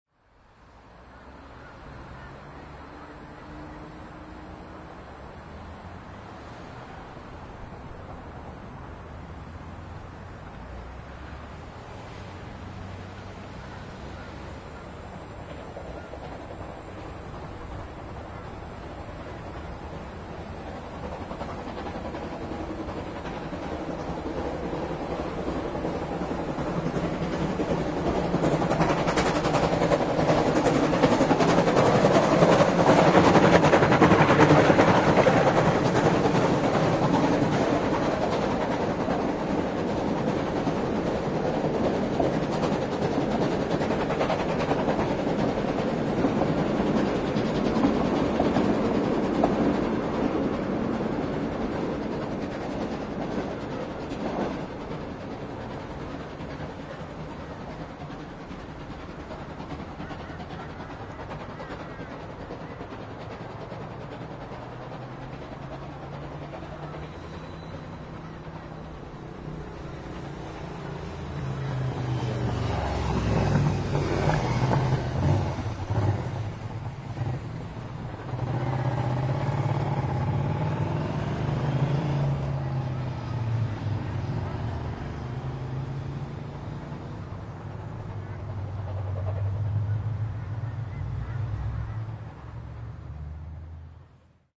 I’m always on the lookout for new recording locations in the City to record these runs, and this time I chose a spot next to the line at Albion to catch the train working up Albion Bank.
Well, I have a utility which has a canopy on the back, so I set the microphone and tripod on the back of it which managed to get above the sound barriers and a nice recording of 1079 was to be had.
As is always the recordists’ lot, something is bound to come along, but at least this time, it was after the train had passed, and it was also a vintage Triumph motorcycle which I think added a very nice classic period ambience to the whole recording.